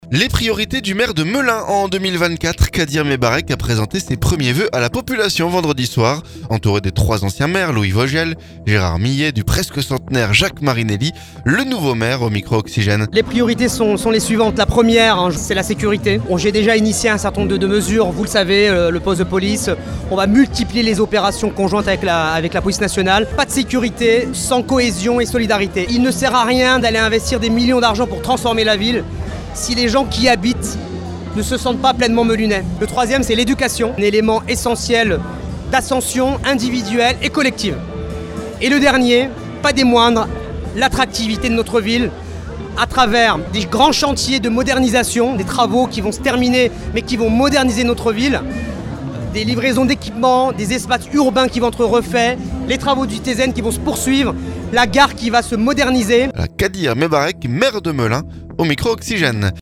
Le nouveau maire au micro Oxygène.